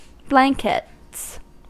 Ääntäminen
Ääntäminen US UK : IPA : /blænkɛts/ Haettu sana löytyi näillä lähdekielillä: englanti Käännöksiä ei löytynyt valitulle kohdekielelle.